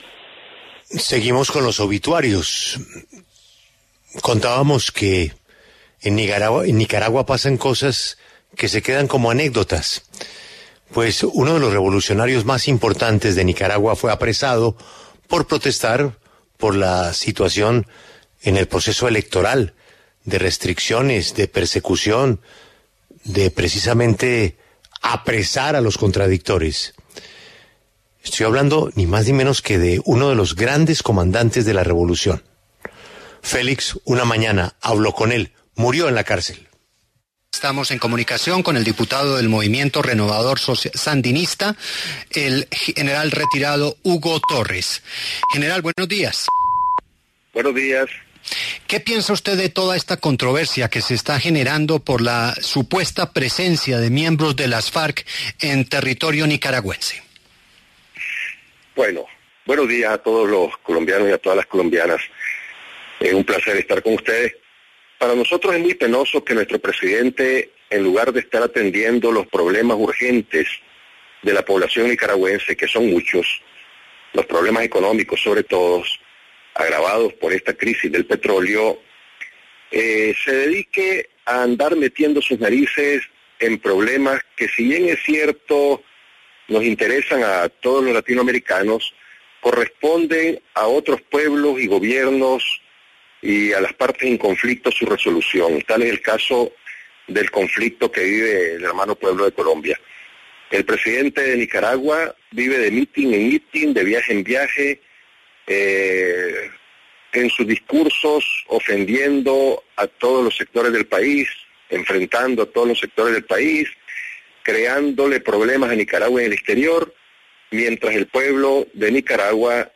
El comandante sandinista Hugo Torres pasó por los micrófonos de La W y reveló detalles sobre el actual régimen de Daniel Ortega en Nicaragua.